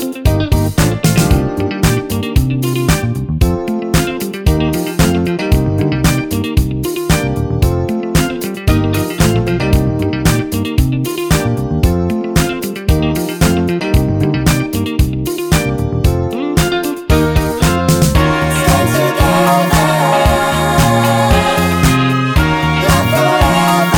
no Backing Vocals Soul / Motown 4:01 Buy £1.50